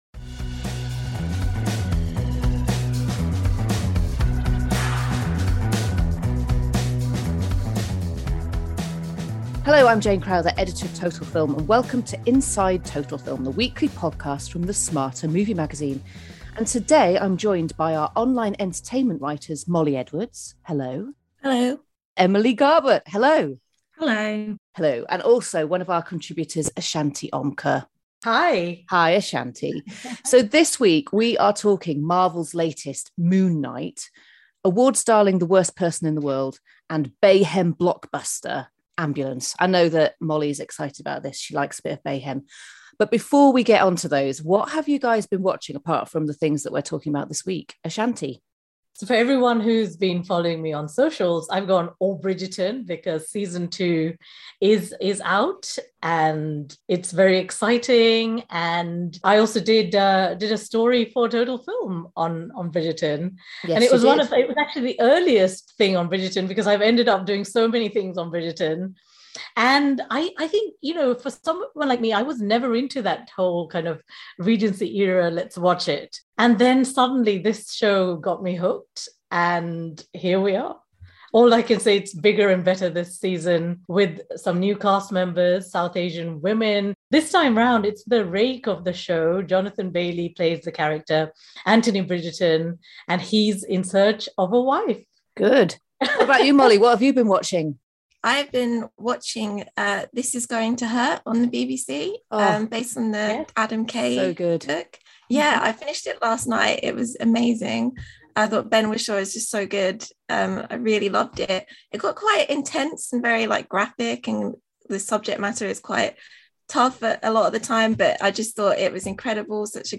Plus, the usual fun, silly voices and viewing recommendations.